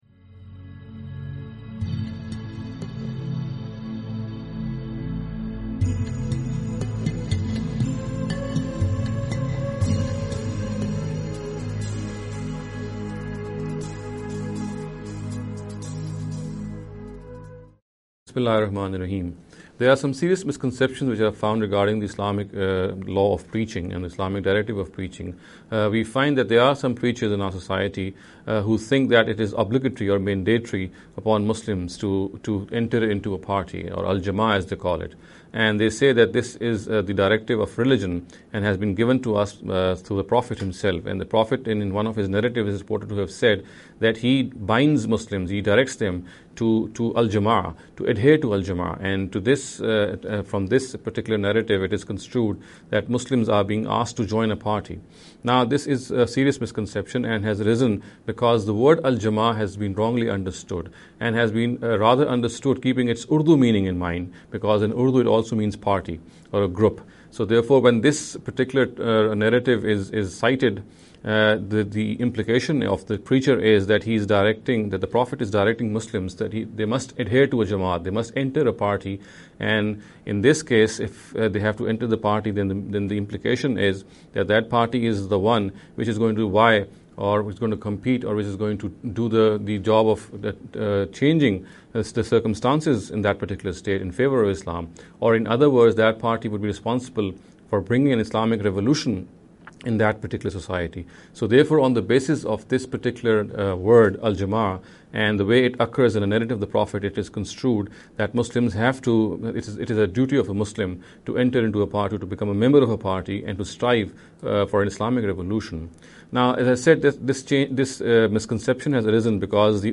This lecture series will deal with some misconception regarding the Preaching Islam.